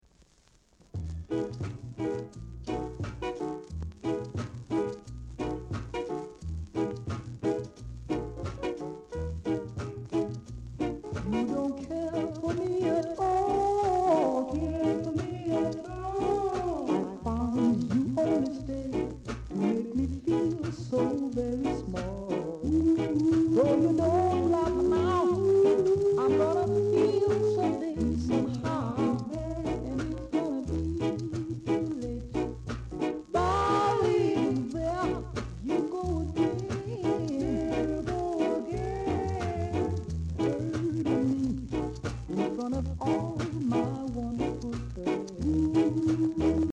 R.Steady Vocal Group